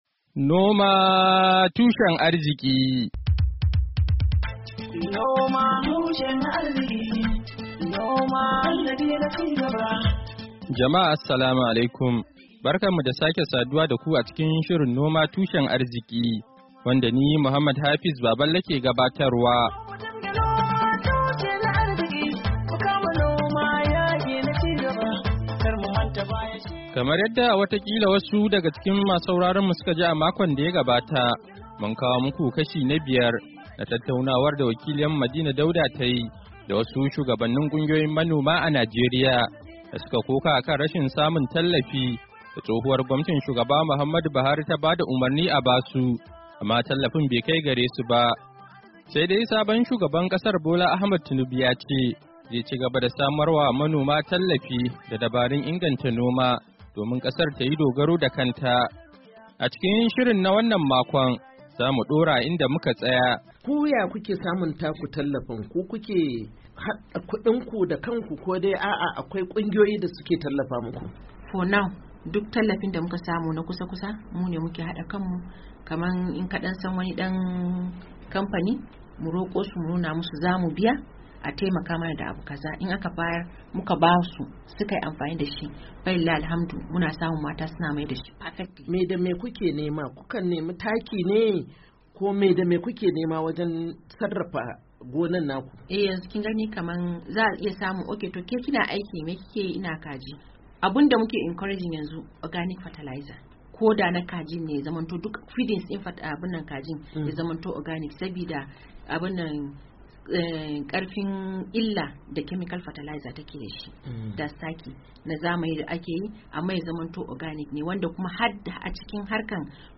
Shirin na wannan makon, zai kawo muku kashi na shida na ci gaba da tattaunawa da shugabannin kungiyoyin manoma mata da maza a Najeriya wanda suka koka cewa tsohuwar gwamnatin Buhari ta ba da umarnin a basu tallafi domin su yi noma har su ciyar da kasa sannan a sayar wa wasu kasashen duniya, amma tallafin bai kai gare su ba.